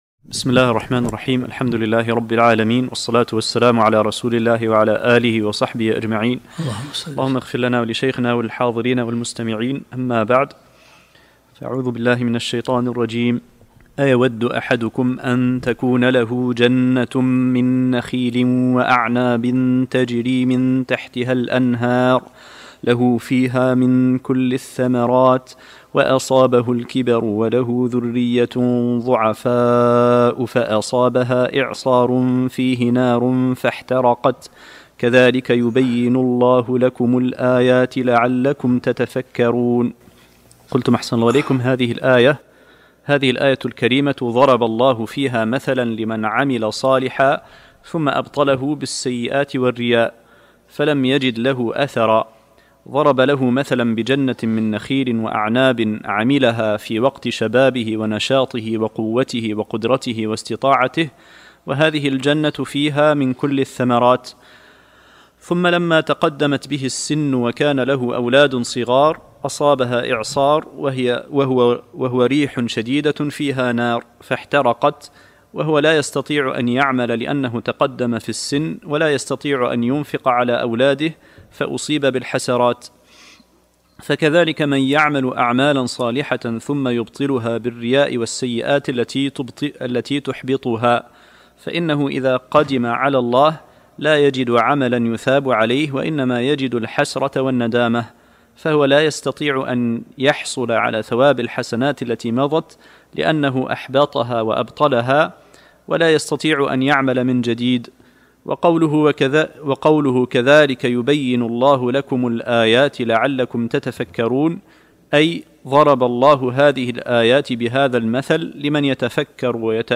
الدرس الثاني والعشرون من سورة البقرة